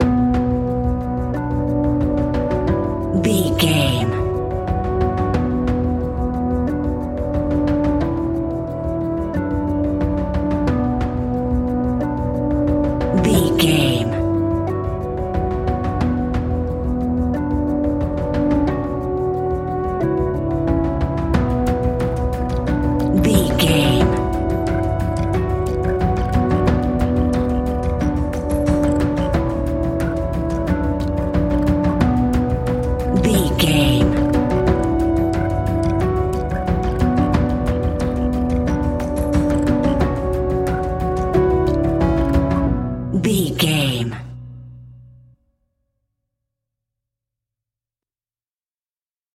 Aeolian/Minor
ominous
dark
eerie
synthesiser
drums
flute
horror music